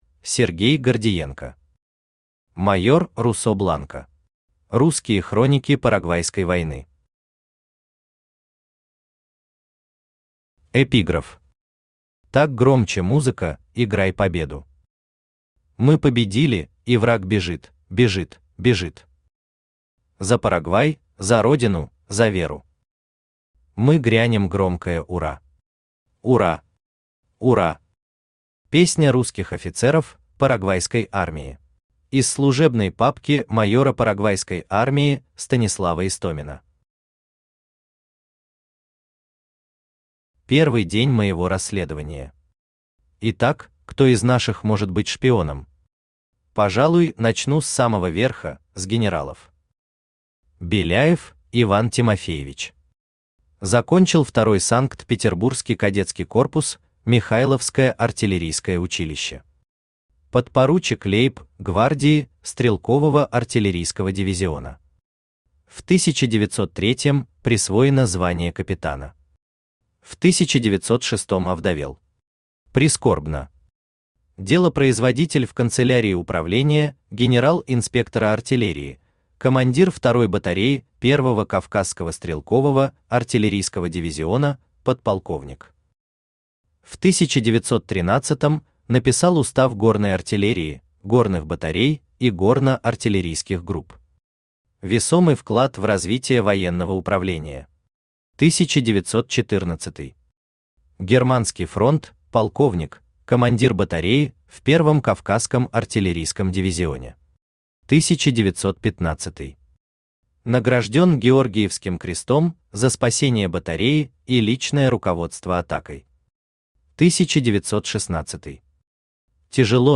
Аудиокнига Майор Русо Бланко. Русские хроники парагвайской войны | Библиотека аудиокниг
Русские хроники парагвайской войны Автор Сергей Гордиенко Читает аудиокнигу Авточтец ЛитРес.